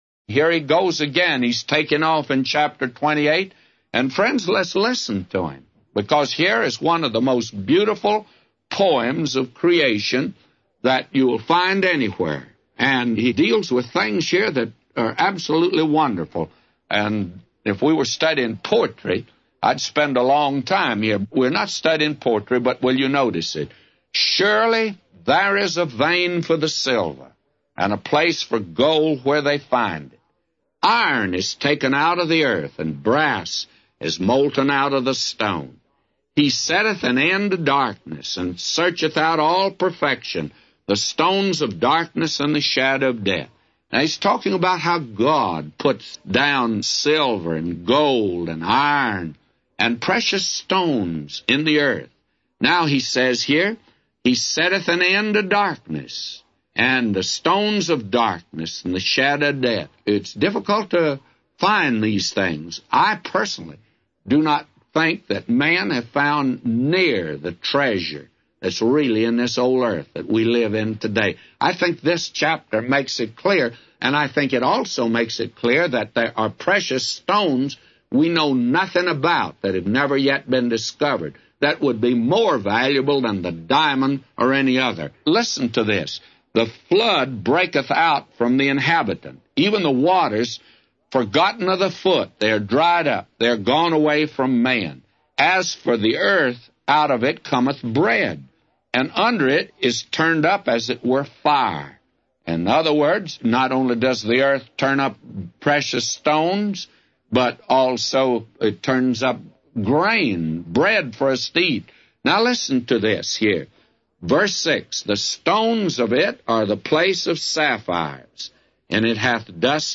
Morning Bible Reading - Job 28